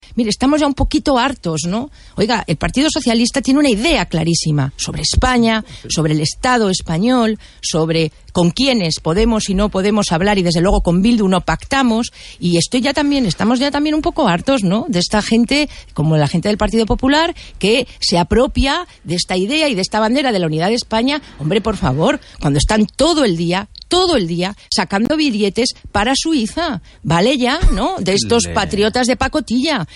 Fragmento de la entrevista de Soraya Rodríguez en Radio 1 de RNE 3/03/2014